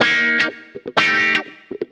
WAV guitarlicks